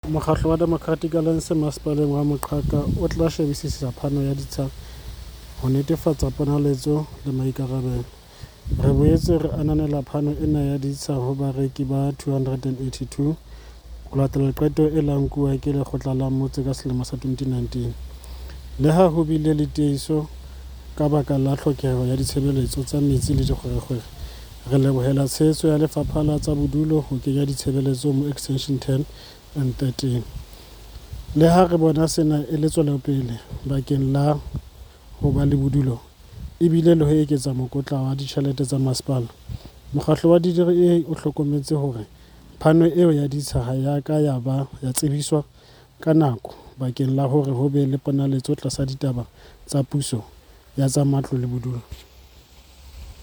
Sesotho soundbites by Cllr Matthews Sepatala Chabalala and Afrikaans soundbite by Cllr Linda Louwrens